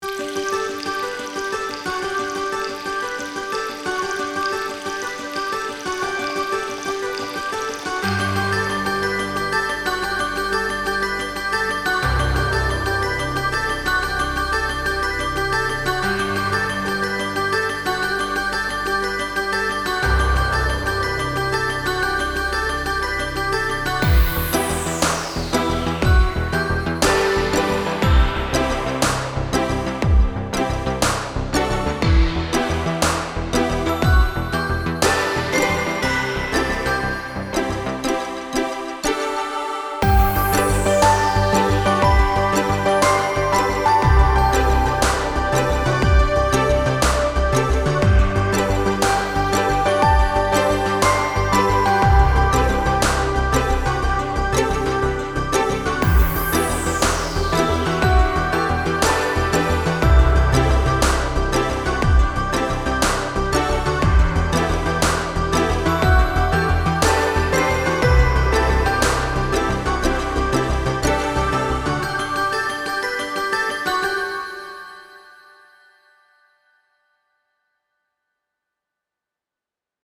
Logic + D50, M1, Omnisphere, Nexus, Polysix.